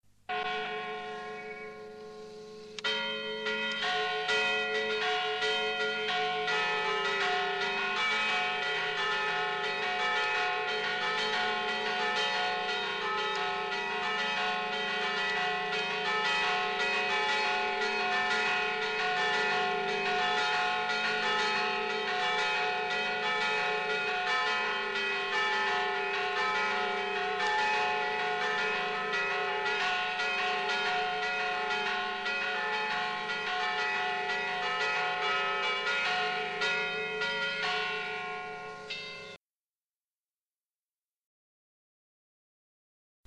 Ovviamente la registrazione è di tipo artigianale ed è realizzata con i mezzi disponibili all'epoca, ma ciò a mio avviso arricchisce ulteriormente il valore di testimonianza di ciò che ascolterete.
I SUONI DELLE CAMPANE DI SAN MICHELE
Festa di San Michele 1980
campane_di_san_michele_1.mp3